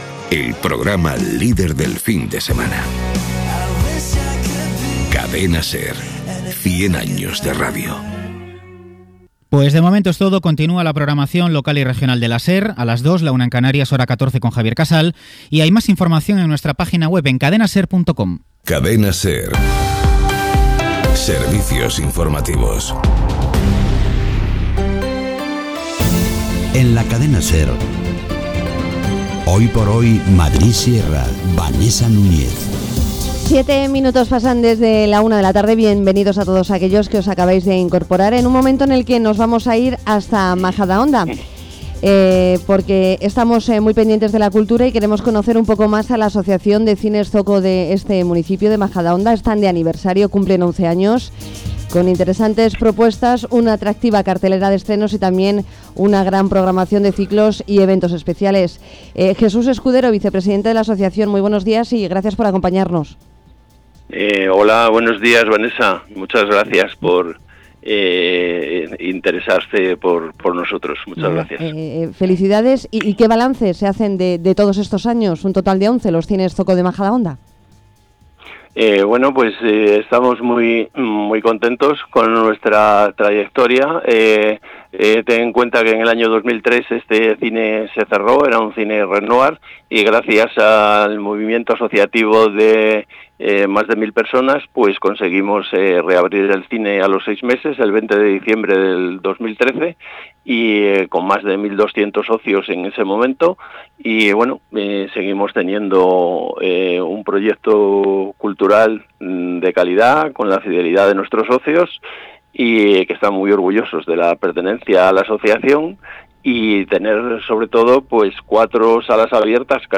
Entrevista en «SER Madrid Sierra» sobre el XI Aniversario